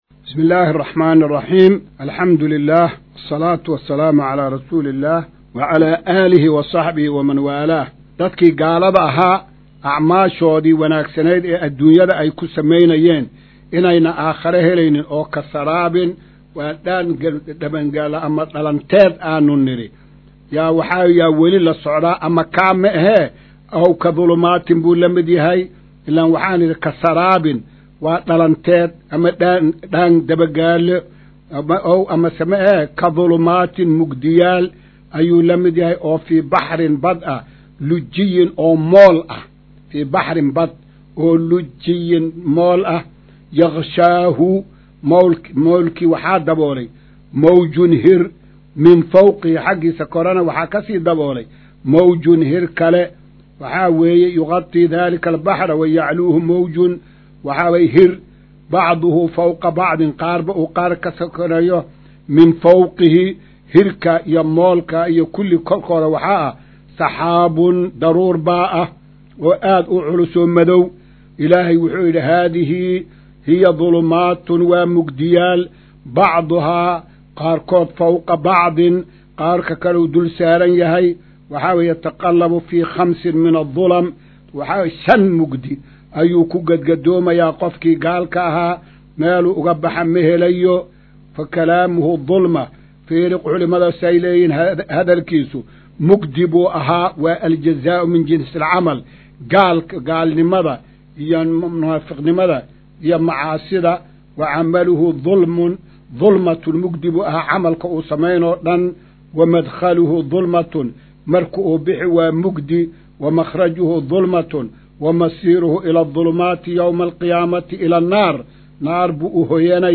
Maqal:- Casharka Tafsiirka Qur’aanka Idaacadda Himilo “Darsiga 172aad”